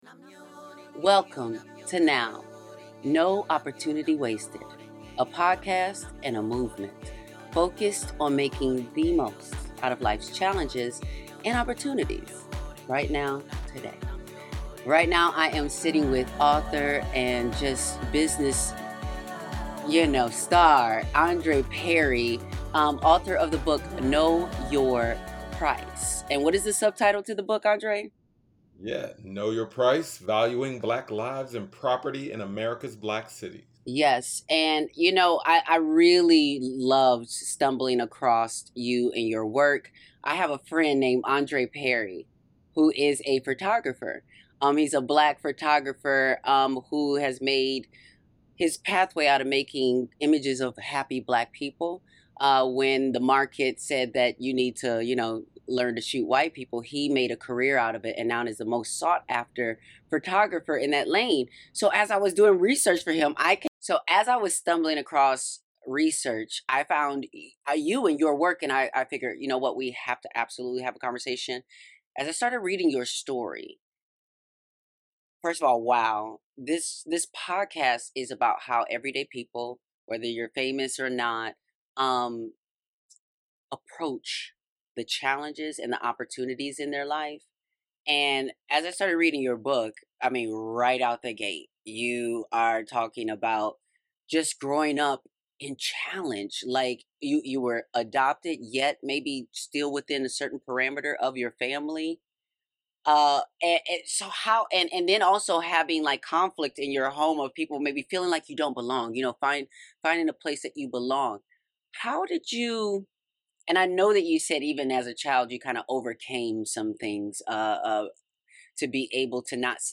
In this insightful episode of "No Opportunity Wasted," Angelica Ross sits down with Andre M. Perry, author of "Know Your Price," to delve into the art of unlocking hidden value and transforming life's challenges into opportunities for personal growth and social empowerment. The conversation explores the intersection of personal experiences and societal structures, emphasizing the importance of valuing Black lives, property, and communities.